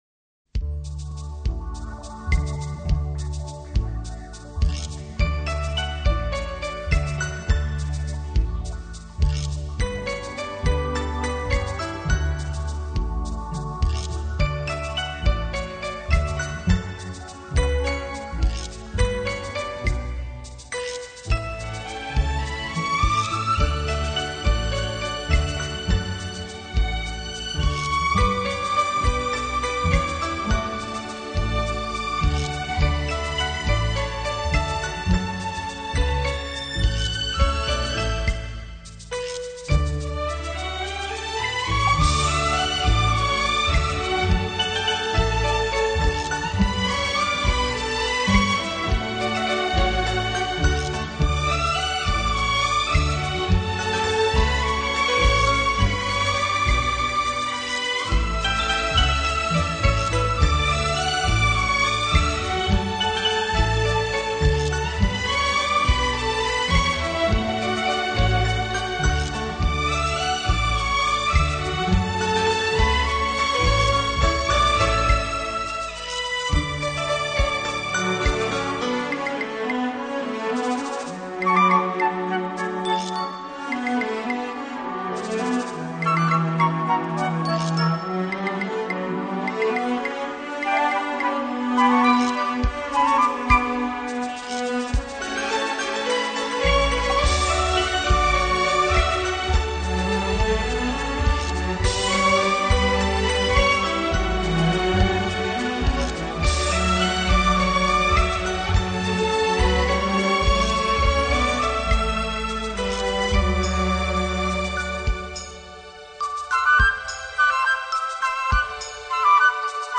鏗鏘有力、乾淨俐落的節奏搭配銅管及打擊樂器，
使音樂充滿理性及豪邁氣概，